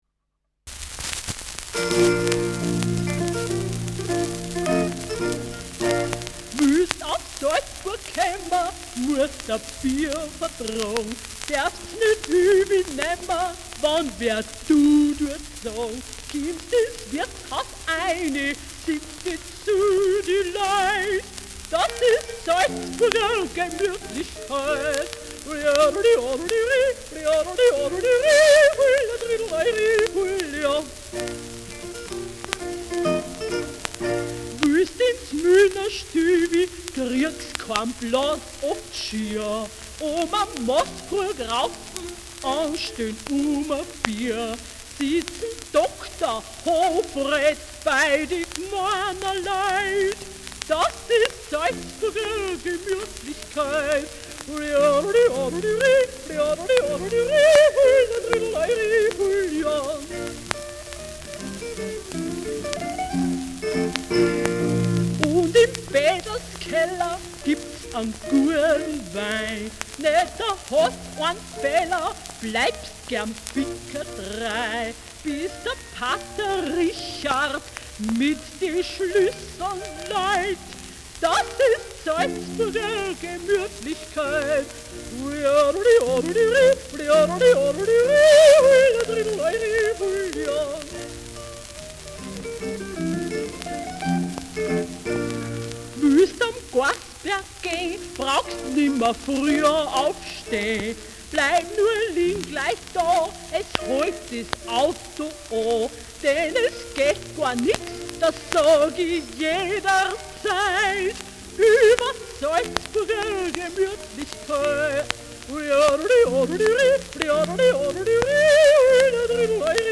mit Zitherbegleitung
Schellackplatte
Stubenmusik* FVS-00016